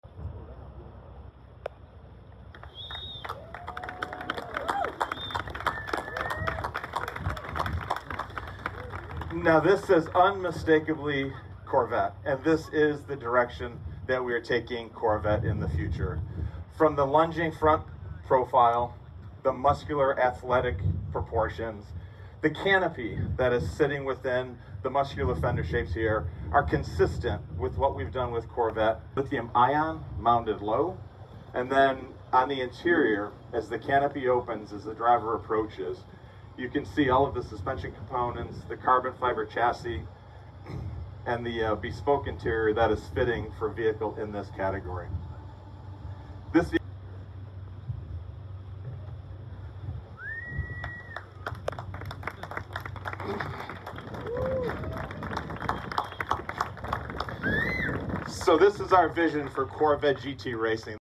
2000hp+ Chevrolet Corvette CX and sound effects free download